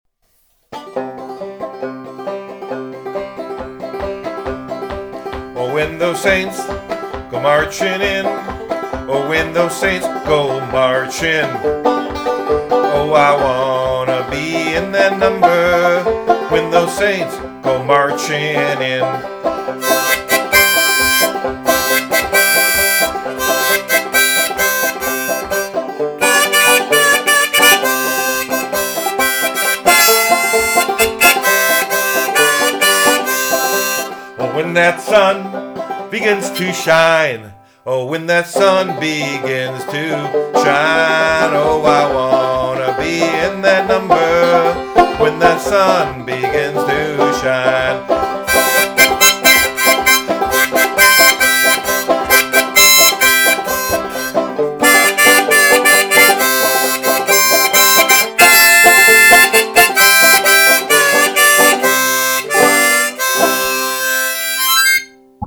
Banjo Man